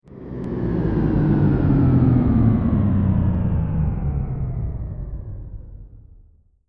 دانلود آهنگ کشتی 7 از افکت صوتی حمل و نقل
جلوه های صوتی
دانلود صدای کشتی 7 از ساعد نیوز با لینک مستقیم و کیفیت بالا